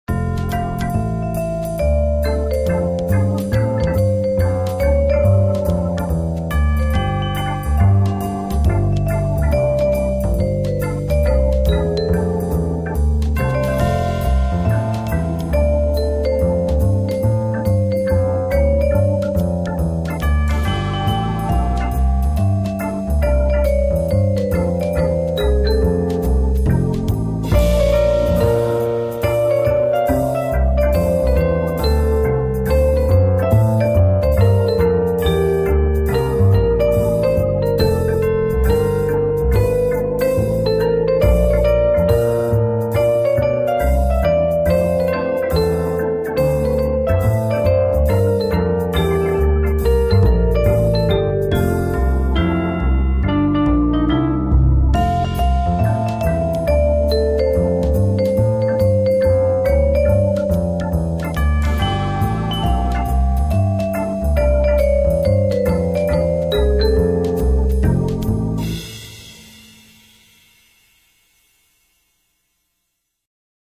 Приятная музыка, напоминающая о Новом годе